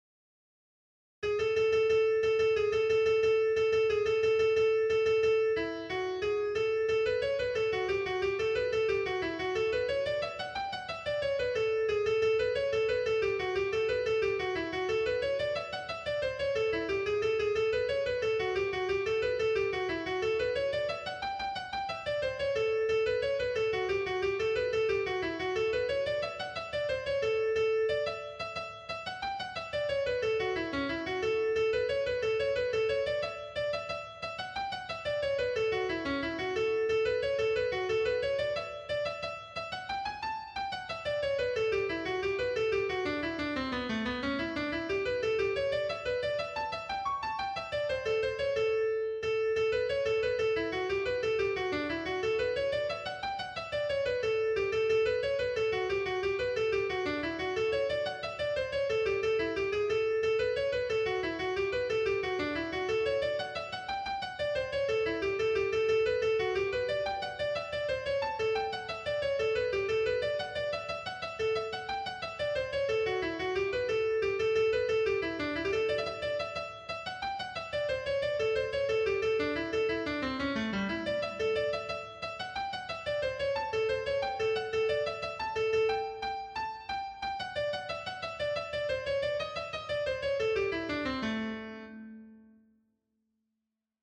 .mp3 file @ 60 bpm